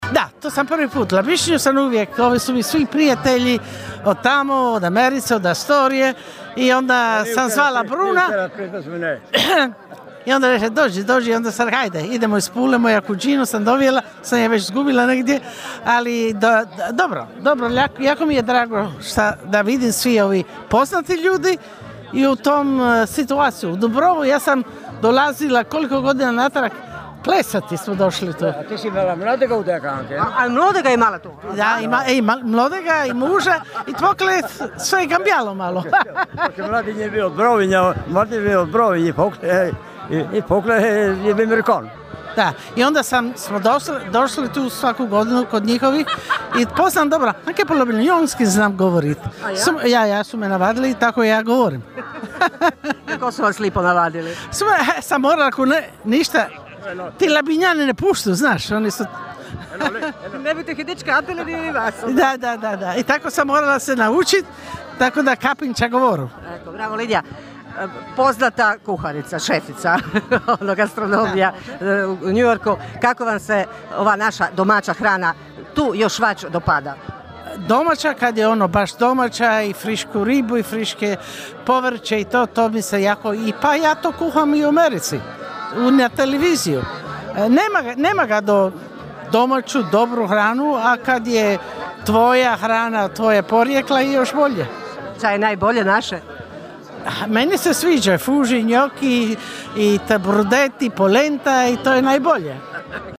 Stotinjak iseljenika koji trenutačno borave u domovini, uglavnom iz SAD-a, ali i Kanade te europskih zemalja Francuske i Njemačke, okupilo se sinoć u Konobi 'Bukaleta' na Dubrovi, na Godišnjem susretu iseljenika Labinštine.